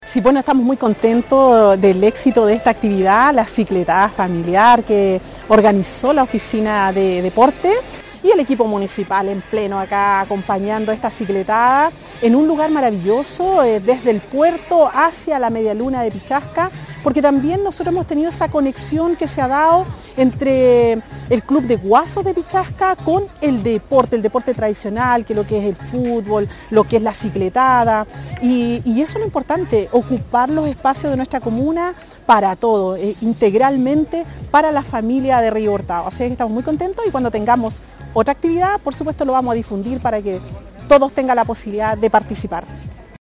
La alcaldesa Carmen Juana Olivares destacó la realización de actividad física en toda la comunidad.
Cuna-alcaldesa-3.mp3